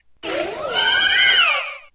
Grito de Florges.ogg
Grito_de_Florges.ogg.mp3